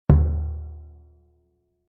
Surdo-2.mp3